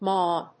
発音記号
• / mɔd(米国英語)
• / mɔ:d(英国英語)